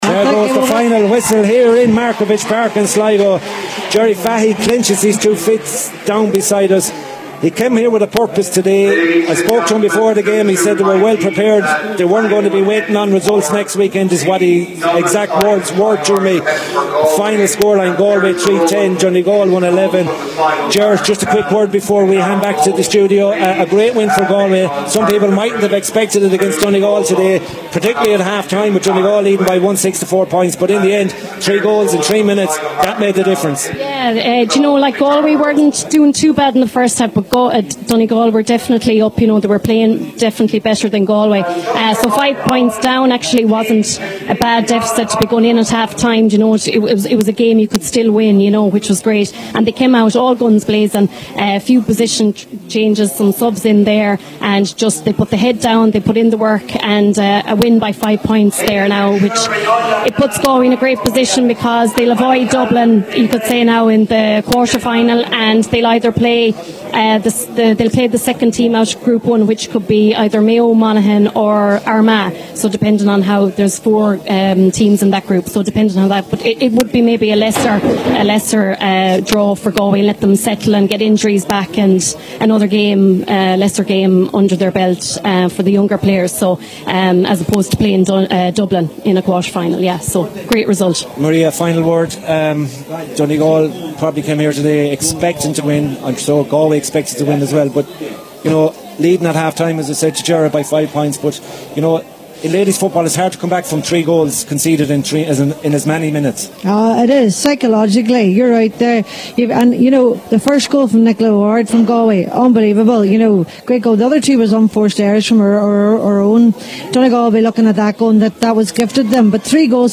have the full time report…